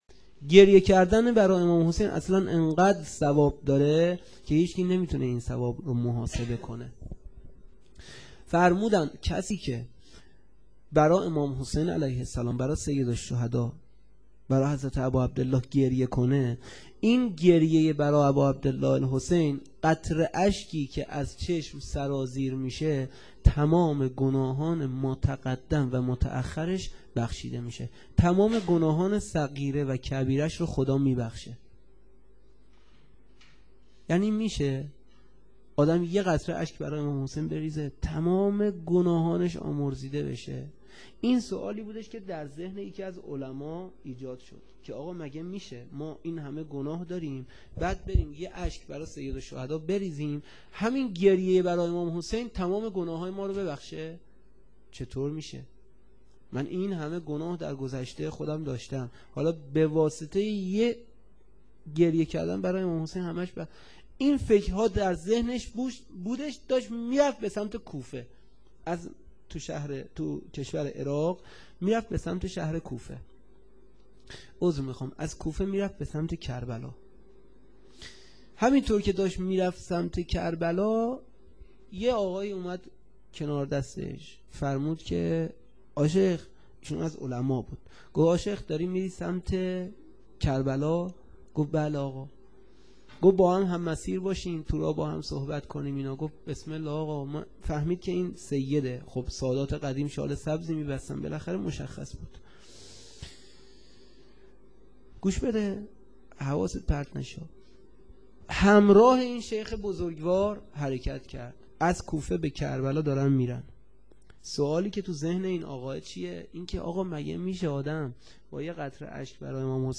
سخنرانی - بخش دوم
sokhanrani-B.wma